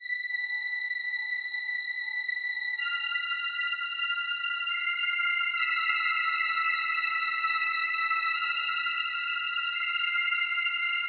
Melota Strings.wav